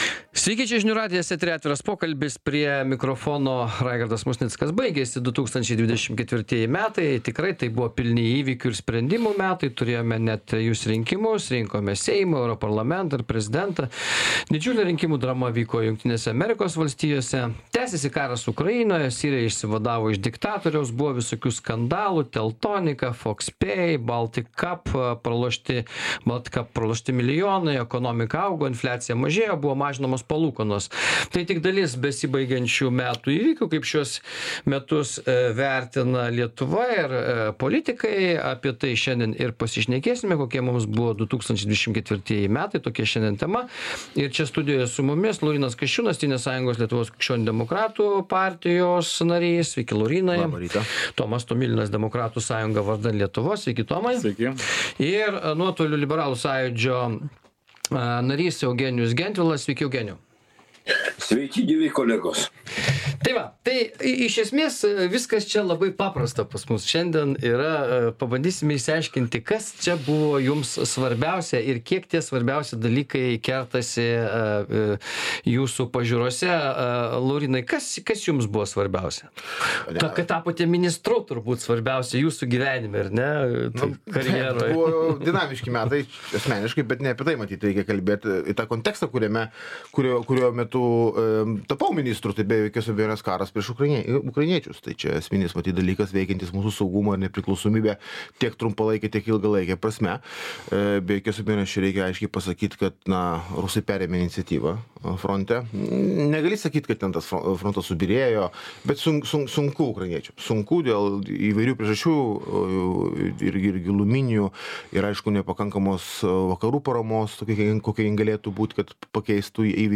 Diskutuoja: konservatorius Laurynas Kasčiūnas, demokratas Tomas Tomilinas ir liberalas Eugenijus Gentvilas.